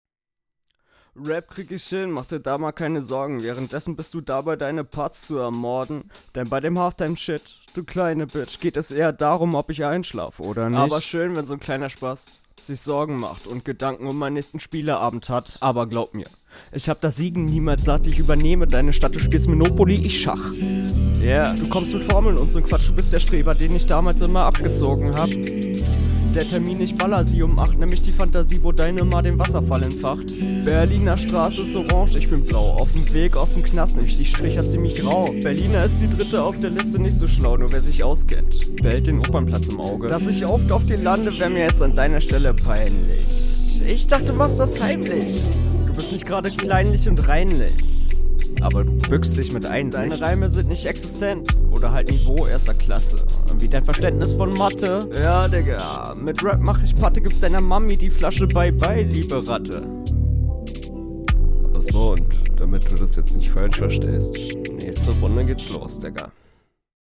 Aber mach mal mehr Druck bei rappen.